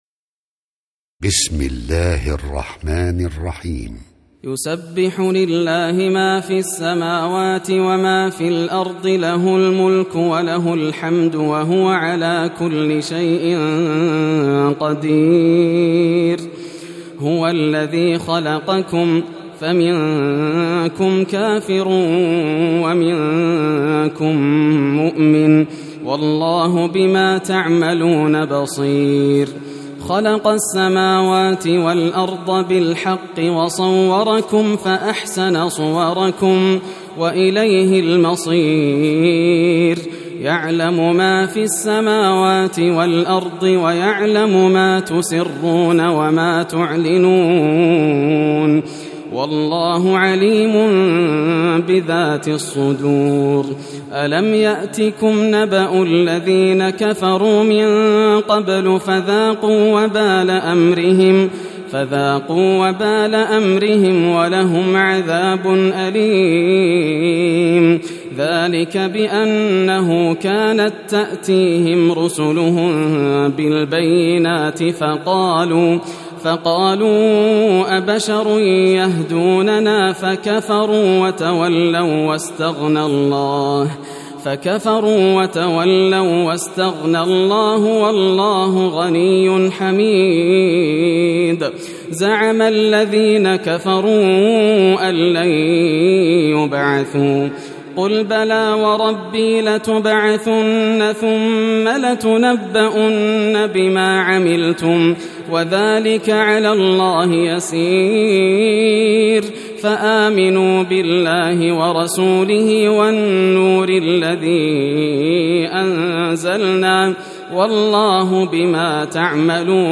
سورة التغابن > المصحف المرتل للشيخ ياسر الدوسري > المصحف - تلاوات الحرمين